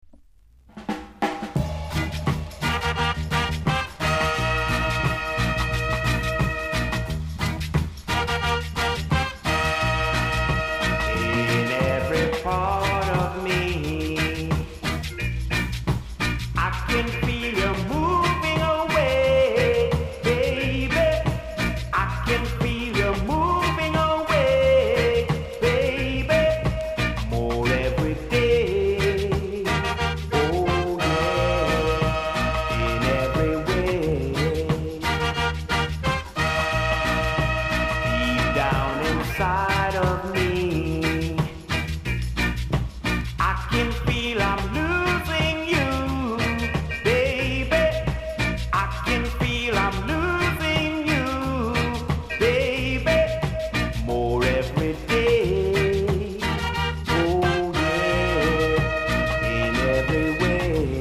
※多少小さなノイズはありますが概ね良好です。
NICE ROCKSTEADY、CALYPSO LP!!